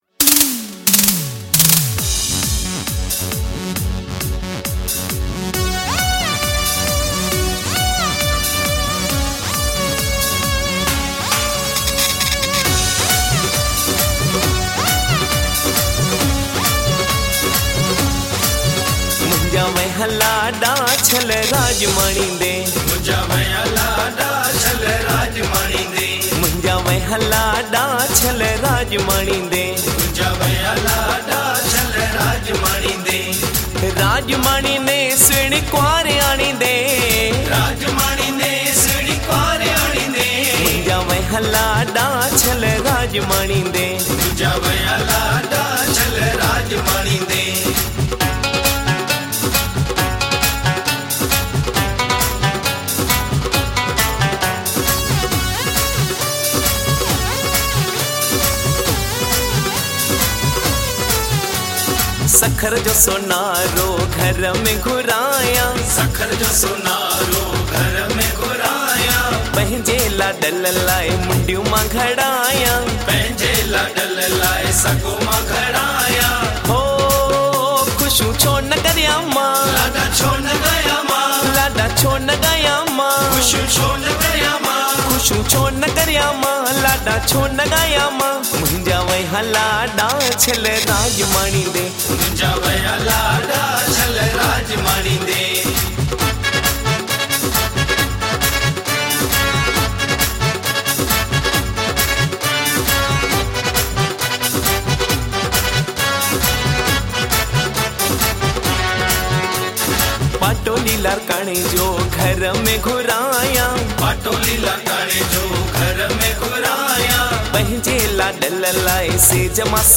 collection of Sindhi wedding songs, a non stop Lada.